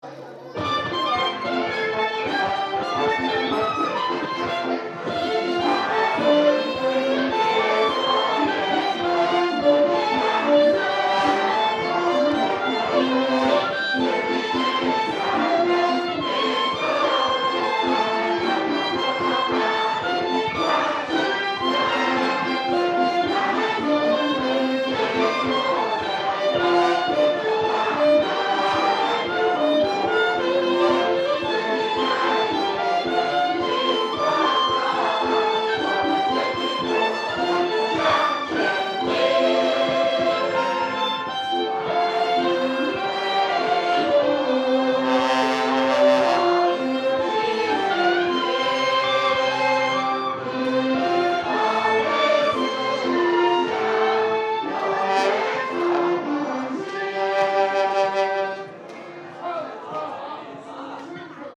Sound recorded in Beijing, Shanghai, Hong Kong and Macao.
- Large choir playing together with random instruments in a park pavilion, a Shanghaian suburb (1) (1:01)
amateur_musicians_shanghaian_suburb_4.mp3